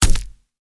BodyHit02.wav